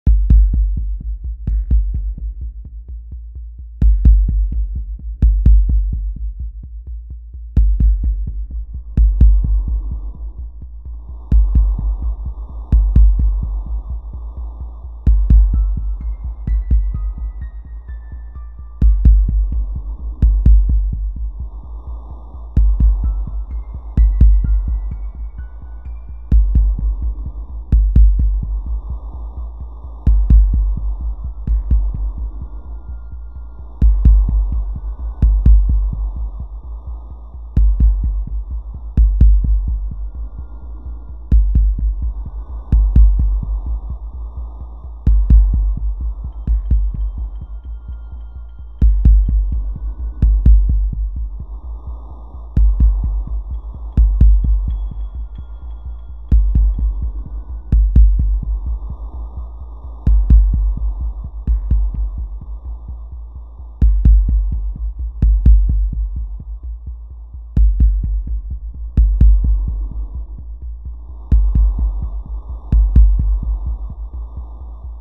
A lurking evil (horror ambience)